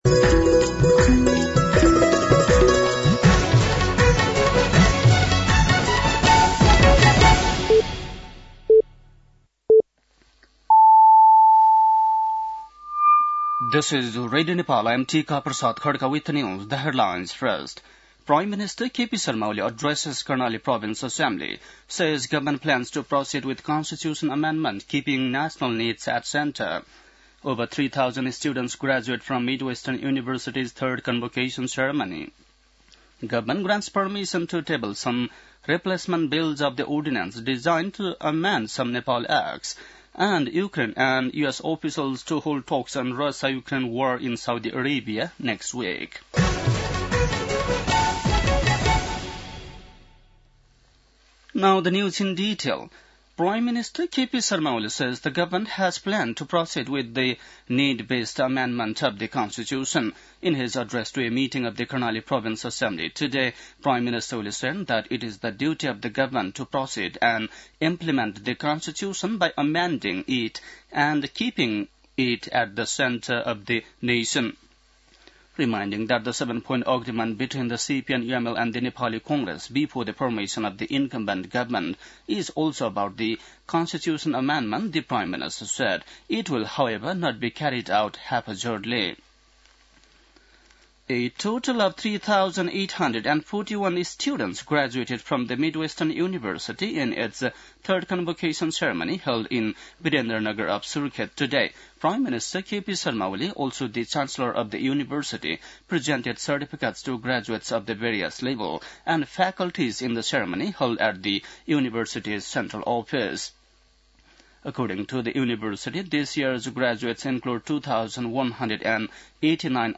बेलुकी ८ बजेको अङ्ग्रेजी समाचार : २४ फागुन , २०८१